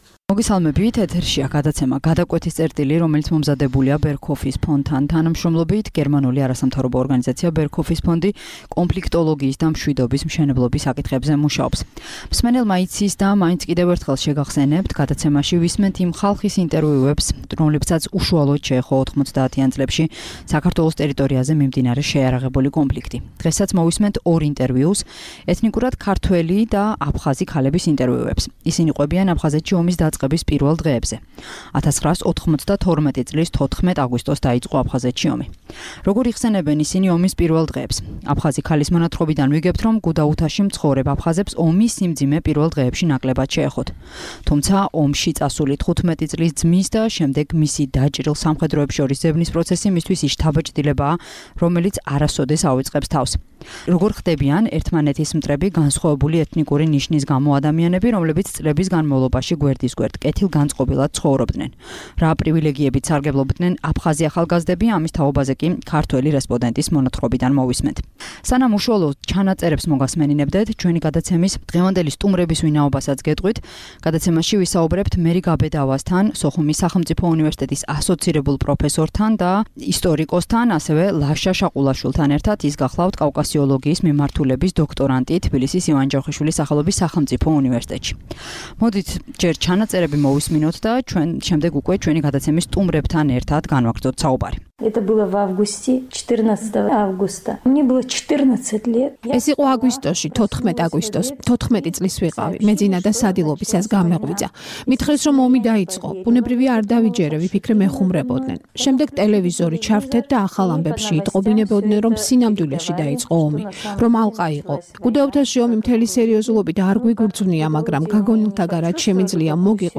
გადაცემაში ორ ინტერვიუს მოისმენთ - ეთნიკურად აფხაზისა და ეთნიკურად ქართველის ინტერვიუებს. ისინი ჰყვებიან აფხაზეთში ომის დაწყების პირველ დღეებზე.